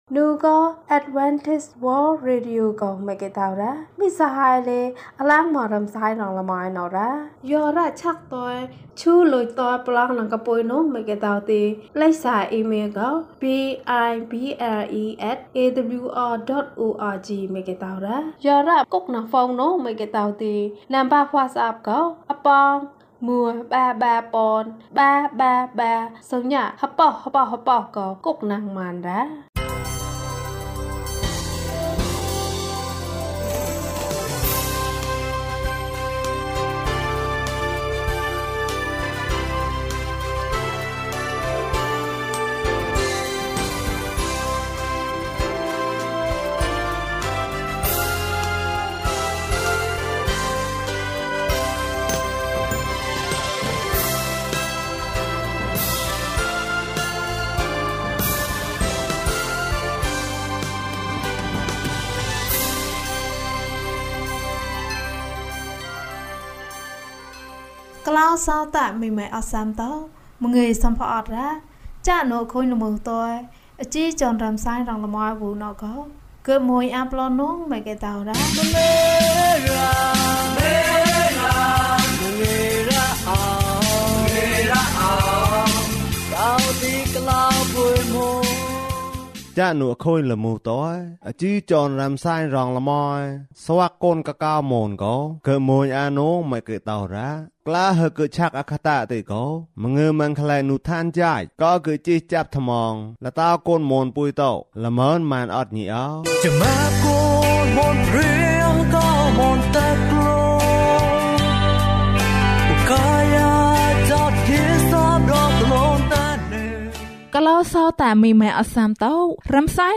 ဘုရားသခင်သည် ချစ်ခြင်းမေတ္တာဖြစ်သည်။၀၄ ကျန်းမာခြင်းအကြောင်းအရာ။ ဓမ္မသီချင်း။ တရားဒေသနာ။